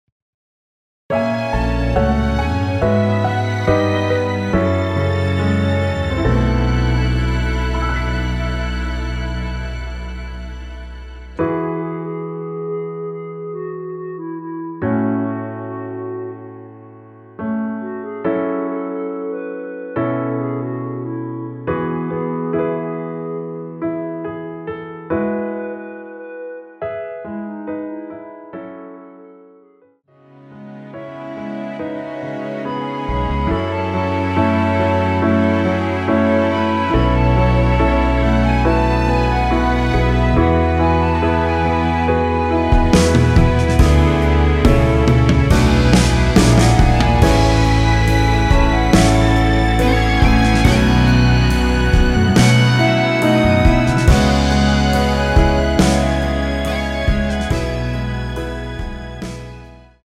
원키에서(+5)올린 멜로디 포함된 MR입니다.(미리듣기 확인)
멜로디 MR이란
앞부분30초, 뒷부분30초씩 편집해서 올려 드리고 있습니다.
중간에 음이 끈어지고 다시 나오는 이유는